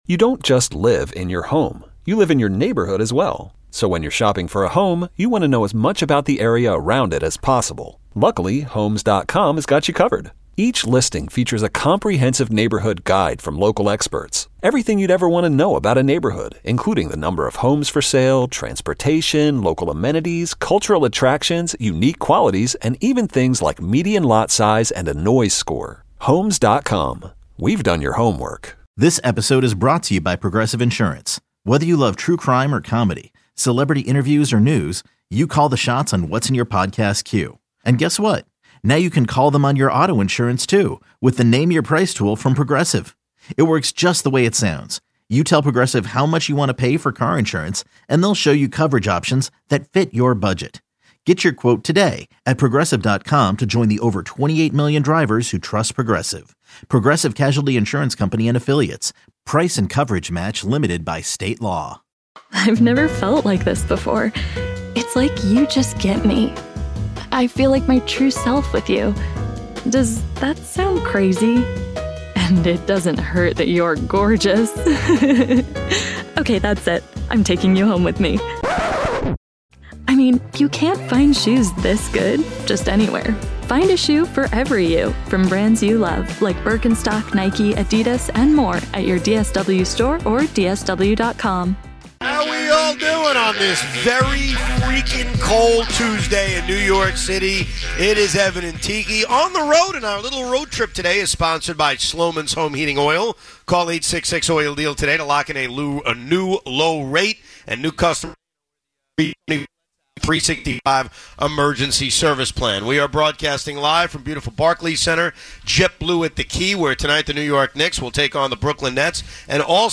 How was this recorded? We are broadcasting live from beautiful Barclays Center,